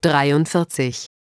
ich habe mal Sprachausgaben für eine Sekunde erstellt sie Anhang .